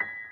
piano32.ogg